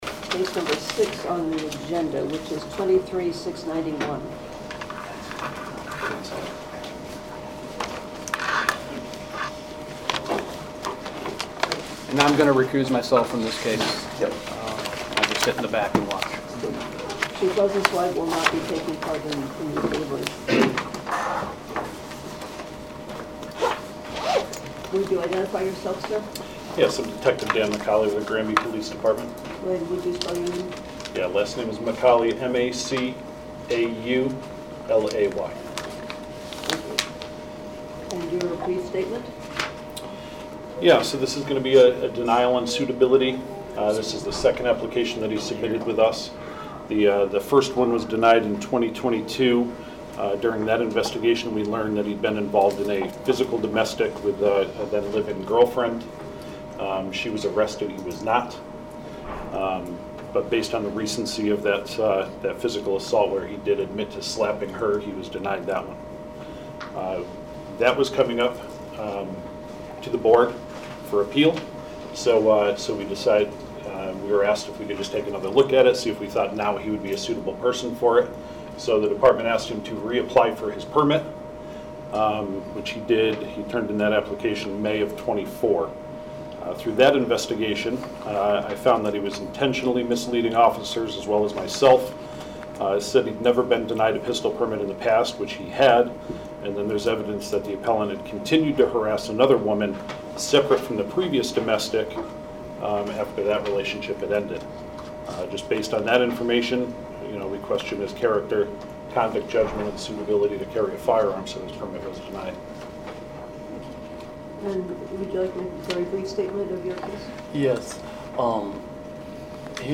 Meeting of the Board of Firearms Permit Examiners